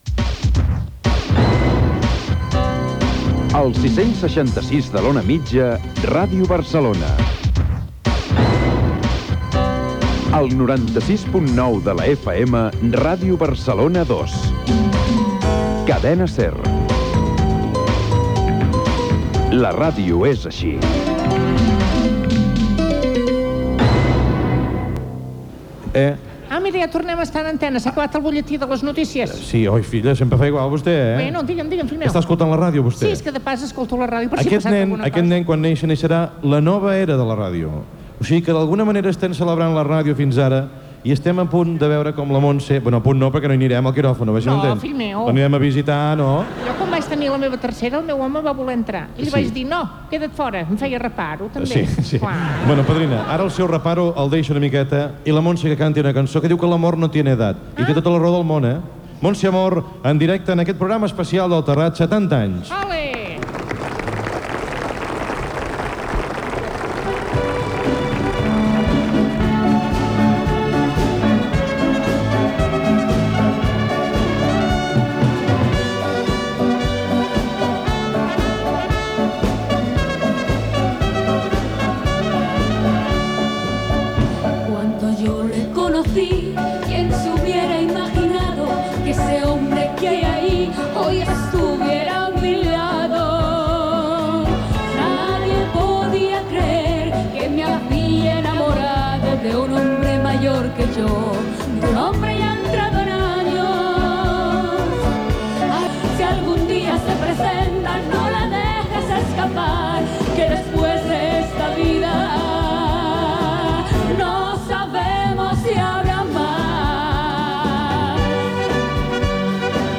Indicatiu de l'emissora.
Entrevista al presentador Joaquín Prat.
Breu intervenció de Matías Prats (pare) i Xavier Sardà. Cançó de l'equip del Terrat.